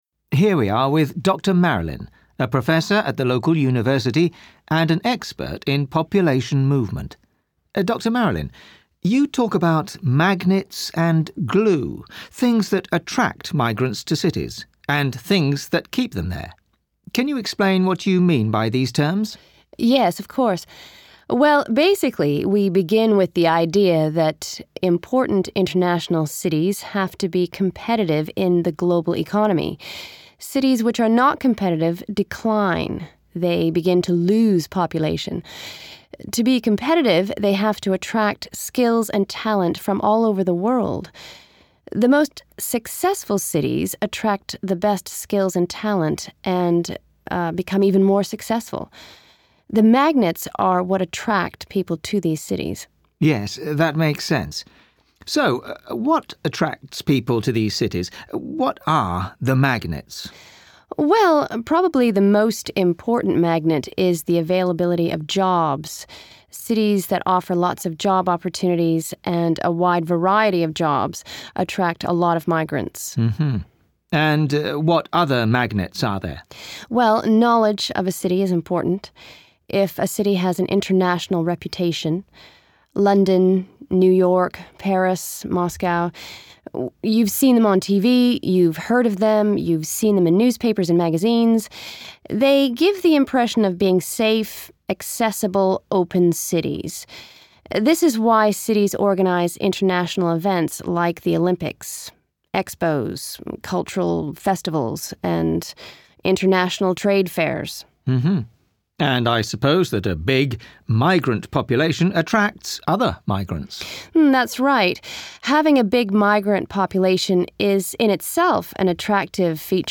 They will begin by exploring why cities have growing / declining populations. They will listen to an interview which describes the concepts of 'magnets' (what attracts people to a city) and 'glue' (what keeps people in a city).